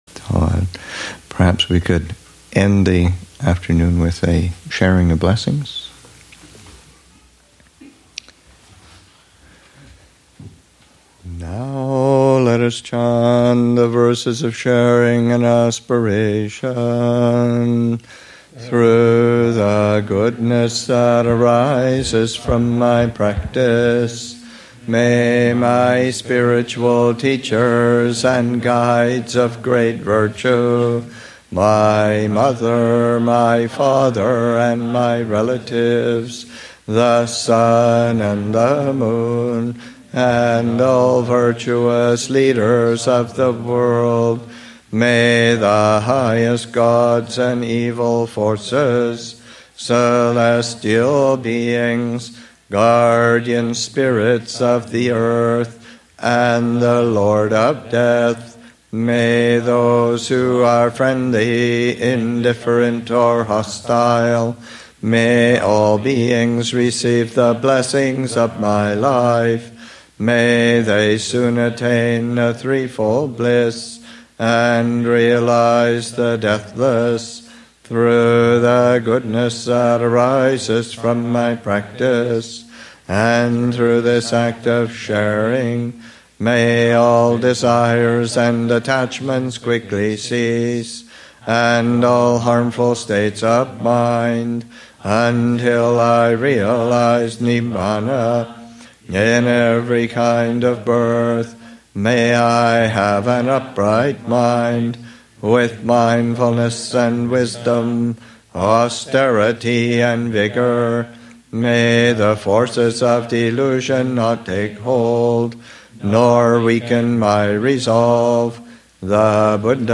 Abhayagiri's 20th Anniversary, Session 16: Closing Remarks
Chanting: The Sharing of Blessings (Amaravati Chanting Book, p. 33).